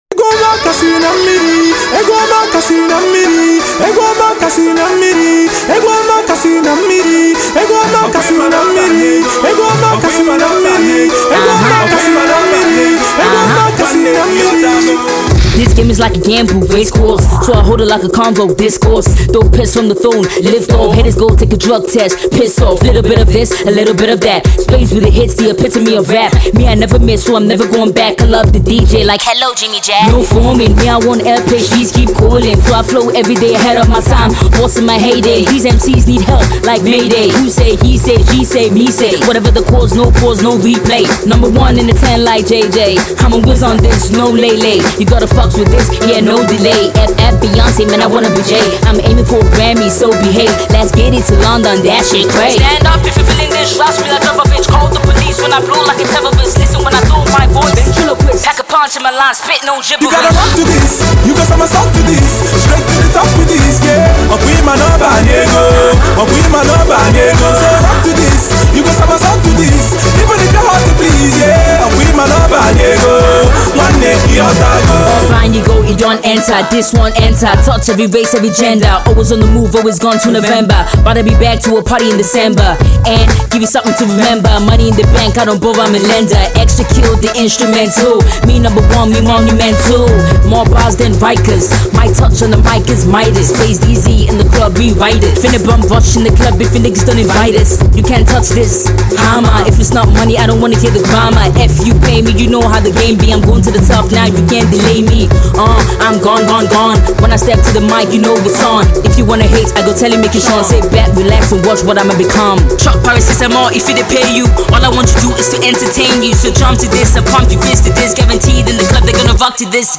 a boisterous Hip-Hop/Dance track